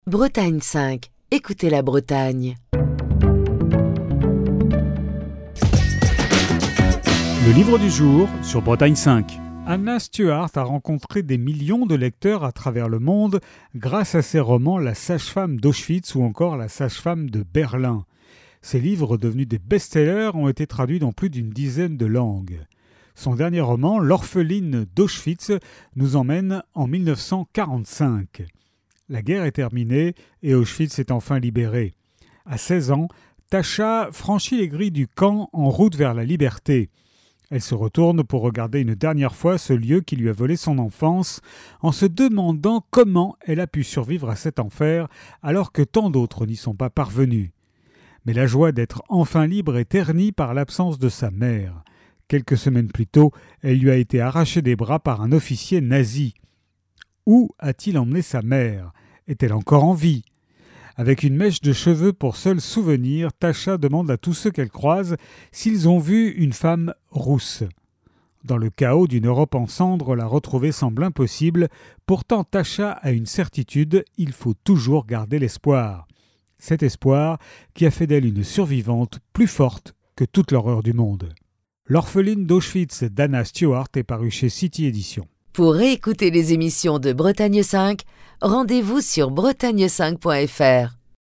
Chronique du 28 mai 2025.